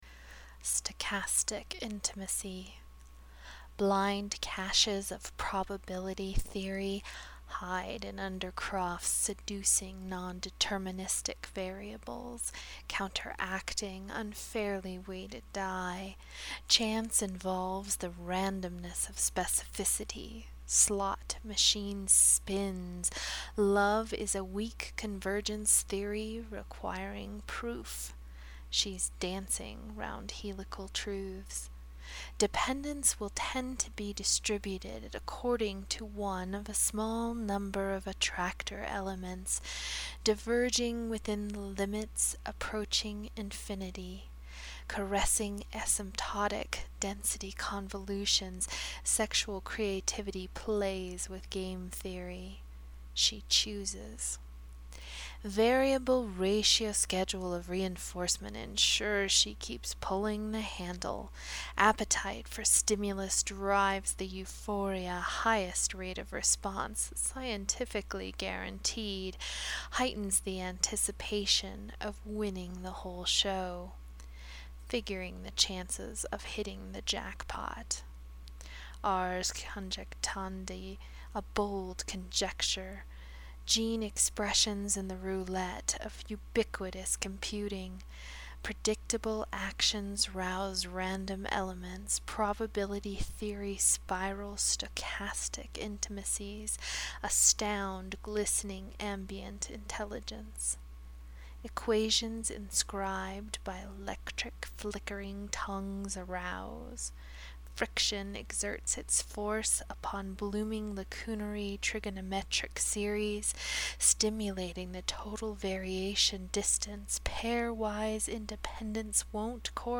Stochastic Intimacy (this is a spoken word piece so click to hear it read)